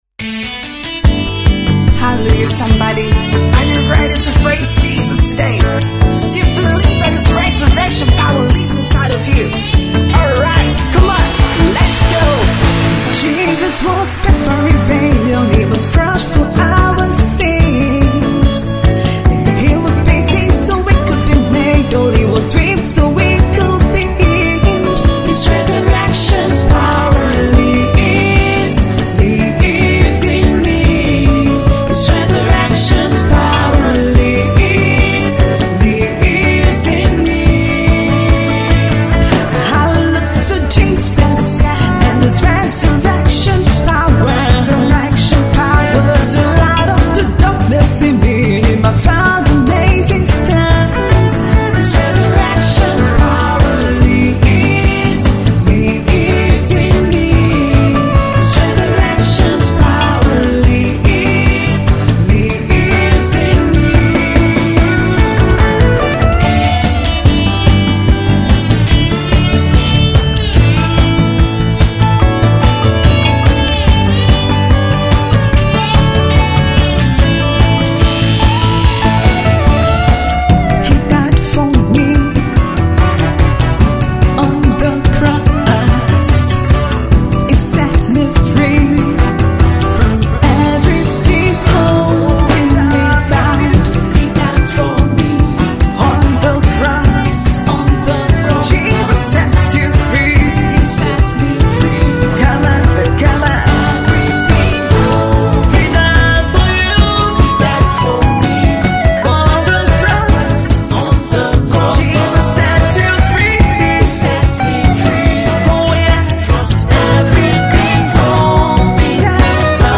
Gospel music minister and presenter